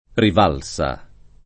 [ riv # l S a ]